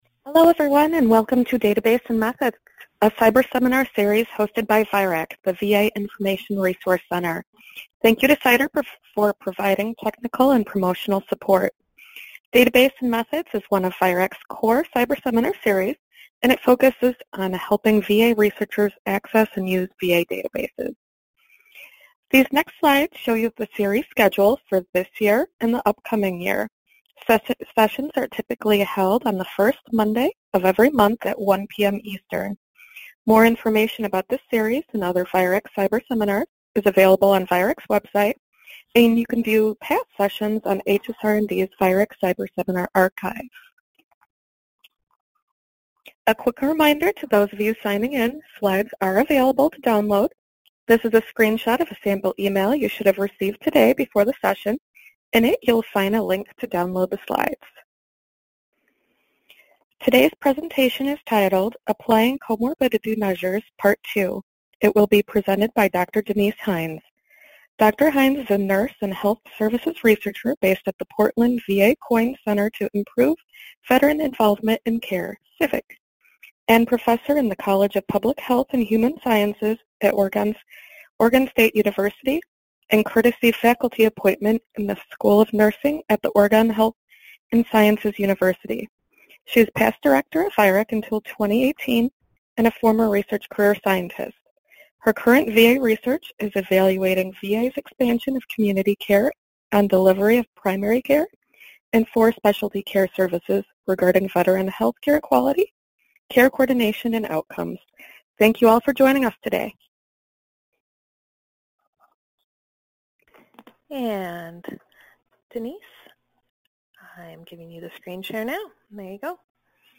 VIReC Database and Methods Seminar